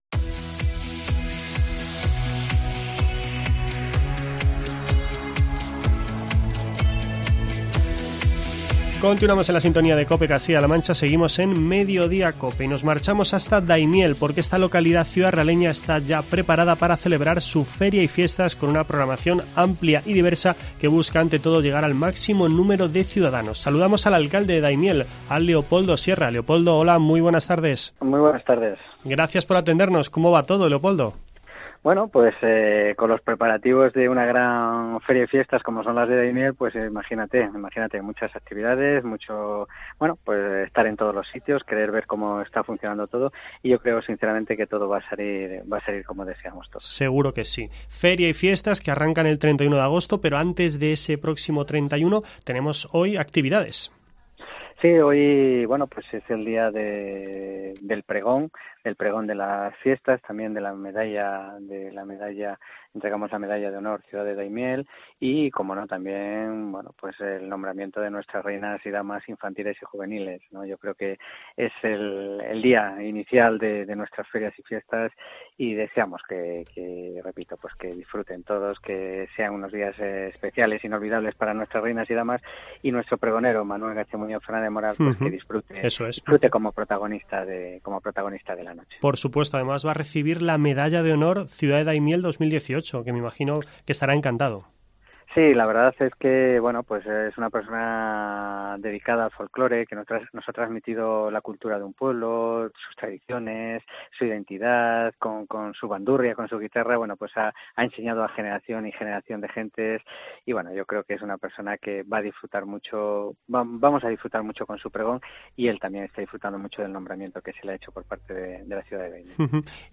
AUDIO: Nos cuenta todos los detalles el alcalde de la localidad, Leopoldo Sierra.